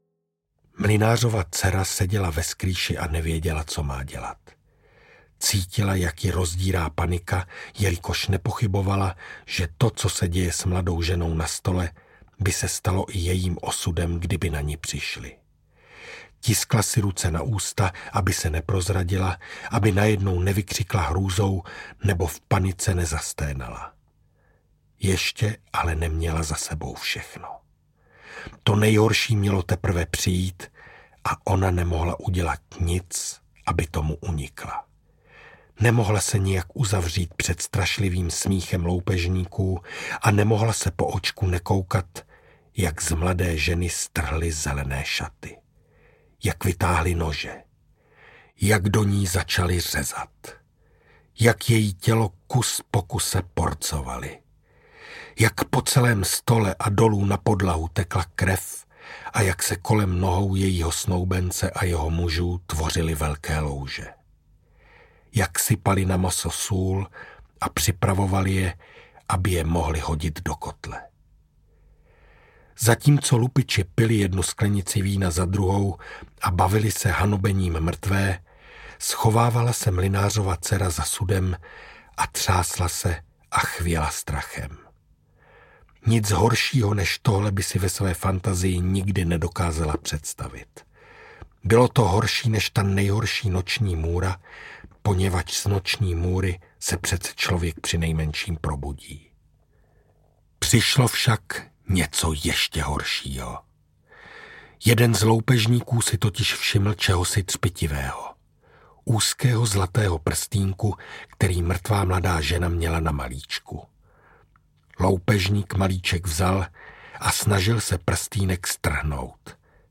Grimmové audiokniha
Ukázka z knihy
Vyrobilo studio Soundguru.